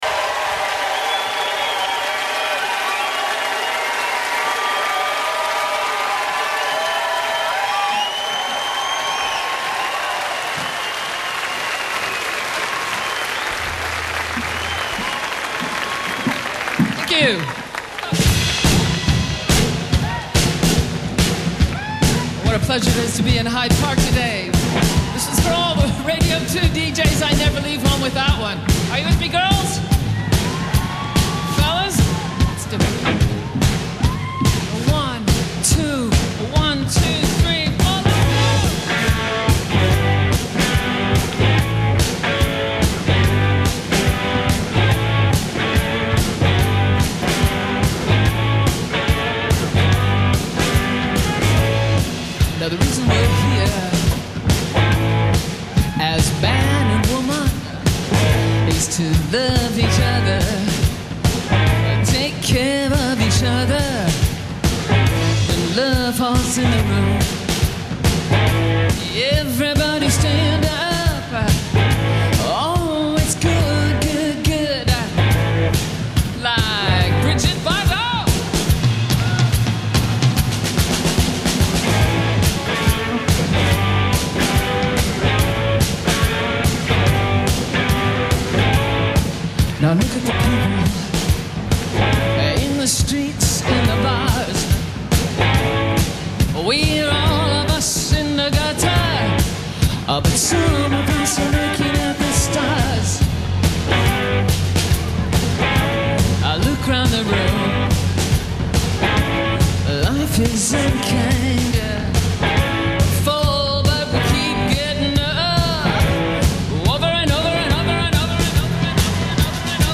Radio 2 Live in Hyde Park
Vocals/Guitar
Bass Guitar
Drums
Keyboard